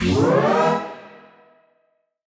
FR_T-PAD[up]-E.wav